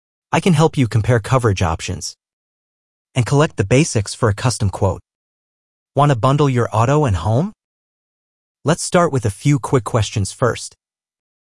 Human Like AI Voice
VerbaCall-AI-Agent-Male-2.mp3